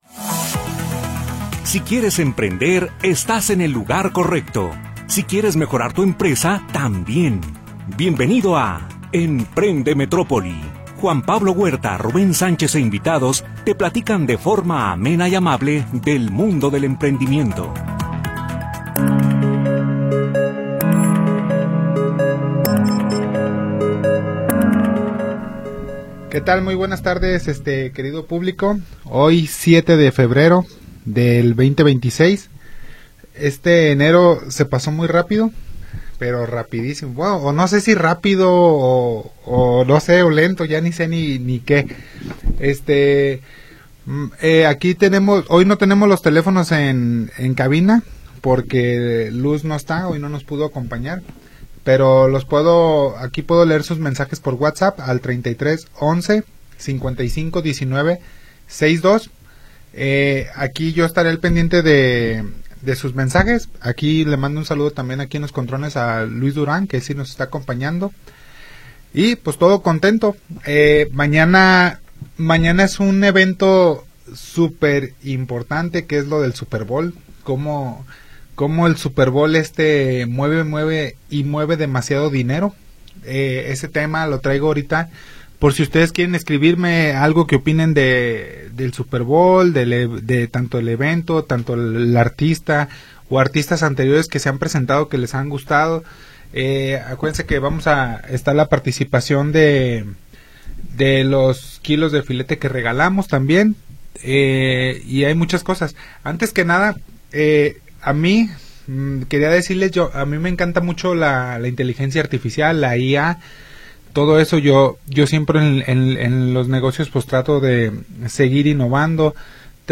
te platican de forma amable y amena acerca del mundo del emprendimiento